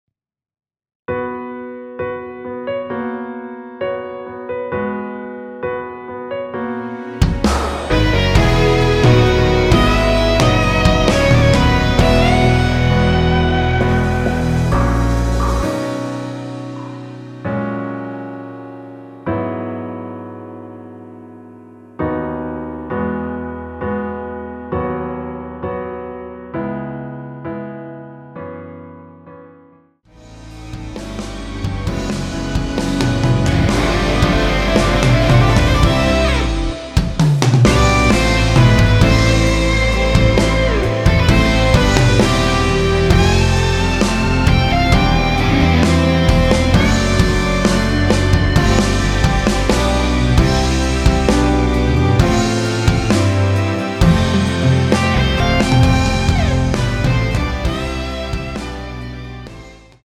원키에서(-2)내린 MR입니다.
-2 음원 mr 까지는 구하기 어려운데 있어서 너무 다행입니다 음질도 좋구요 다운 방법도 편리!
앞부분30초, 뒷부분30초씩 편집해서 올려 드리고 있습니다.
중간에 음이 끈어지고 다시 나오는 이유는